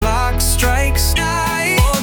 Resonant